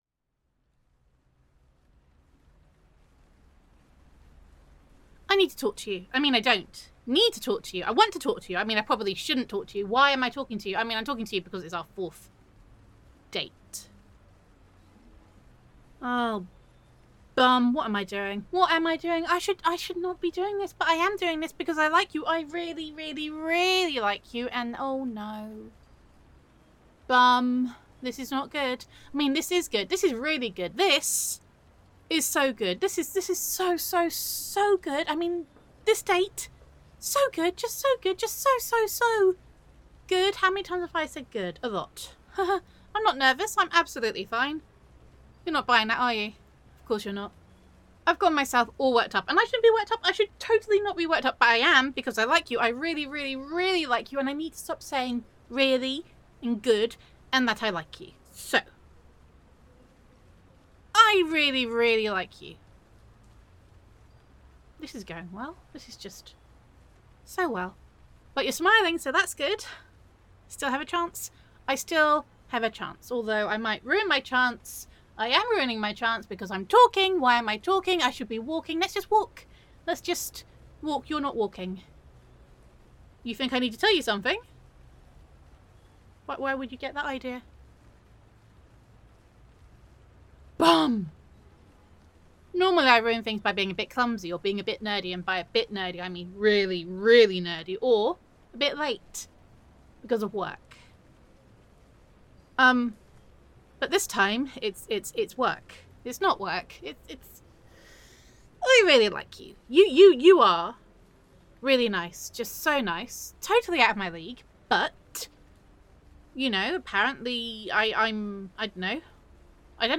[F4A] Please Don’t Be a Superhero [Trainee Supervillain Roleplay][Utterly Ridiculous][Dating Can Be Rough][I Really, Really like You][Awkward AF][Flustered][Gender Neutral][The Girl You Are Dating Is a Henchman with Serious Prospects]